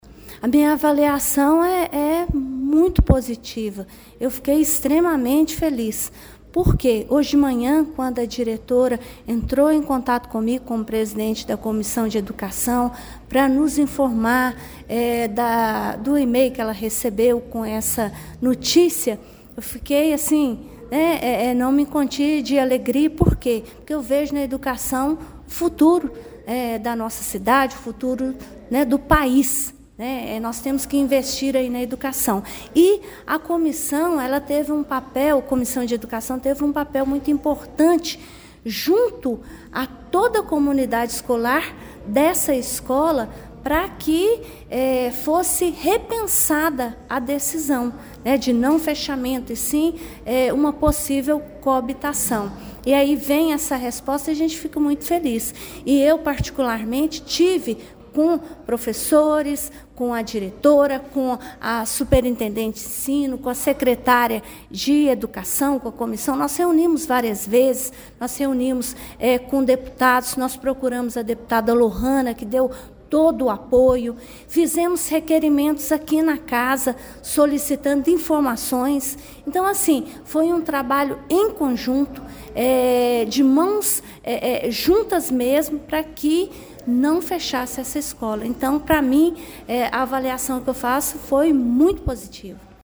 A vereadora e presidente da Comissão de Educação, Cultura e Esporte da Câmara Municipal de Pará de Minas, Irene Susana da Silva de Melo Franco (PSB) falou ao Portal GRNEWS sobre a alegria proporcionada por esta notícia, confirmando que a Escola Estadual Professor Wilson de Melo Guimarães continuará ativa: